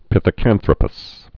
(pĭthĭ-kănthrə-pəs, -kăn-thrōpəs)